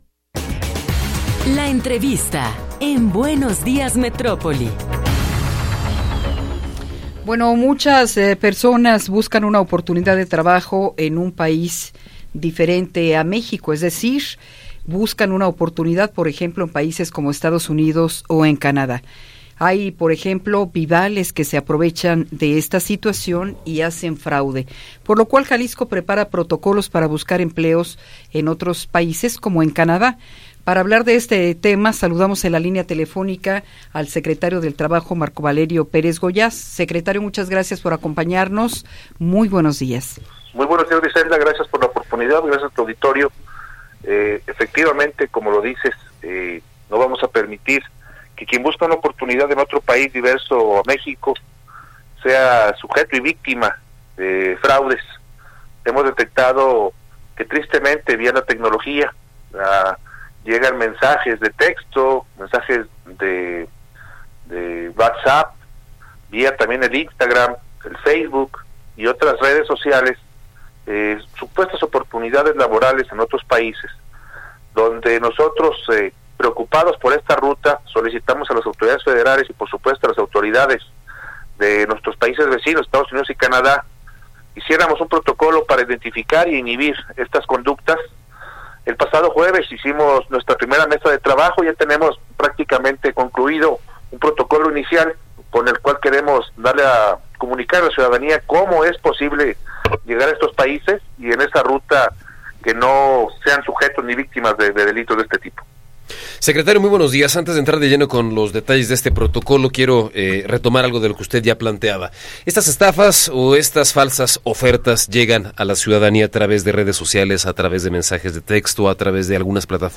Entrevista con Marco Valerio Pérez Gollaz